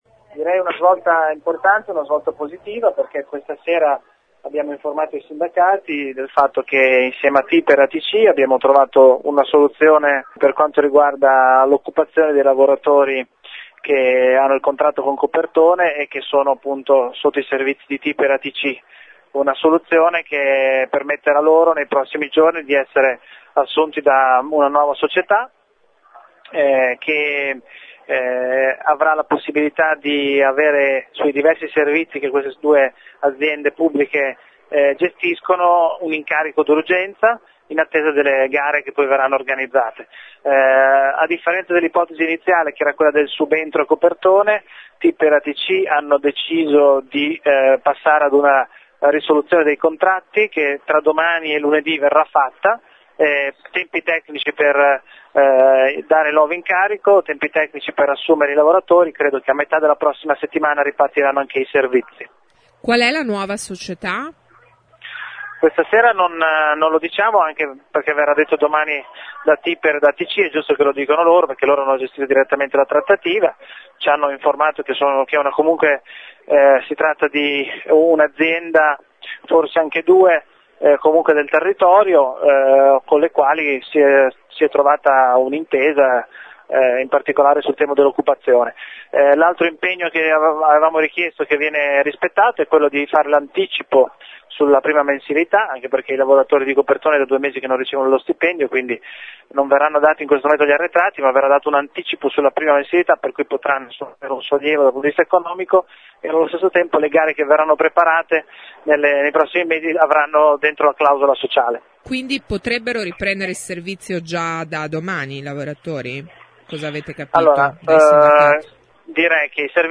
la voce dell’assessore Lepore